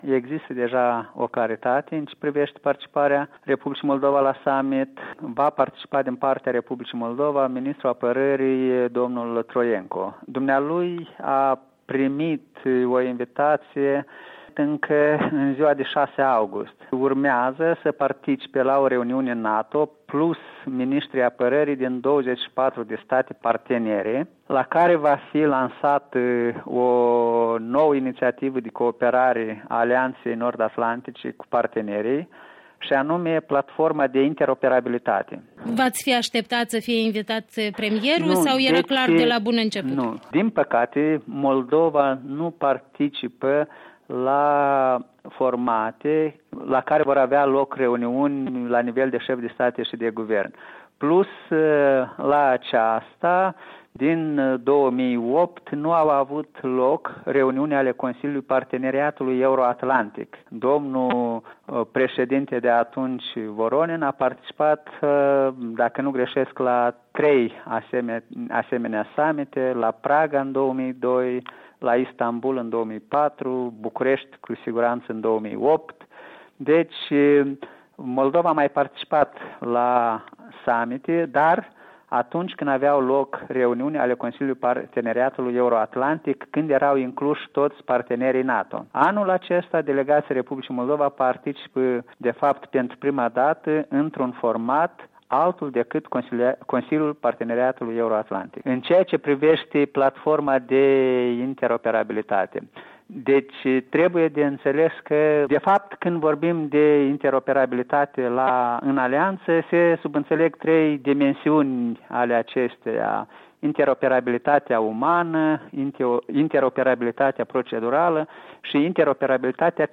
Interviu cu șeful misiunii R. Moldova la NATO